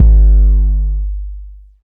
DDWV 808 2.wav